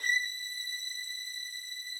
Updated string samples
strings_083.wav